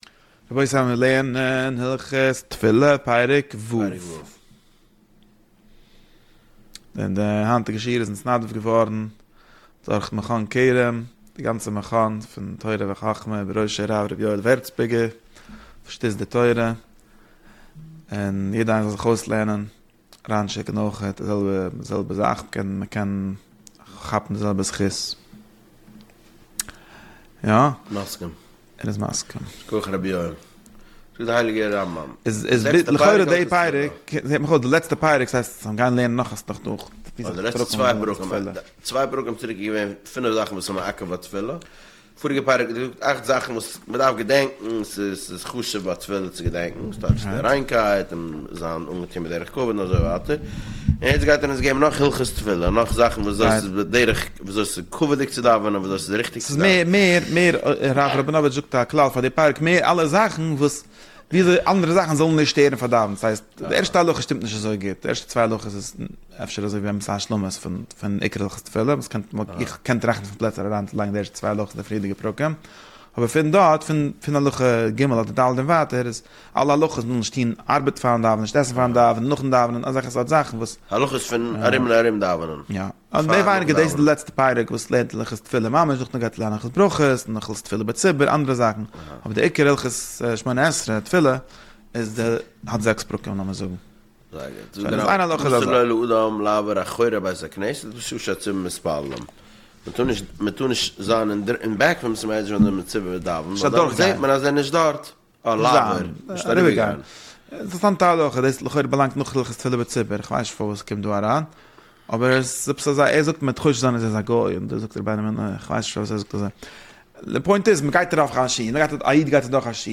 שיעורים על הרמב"ם פרק אחד ליום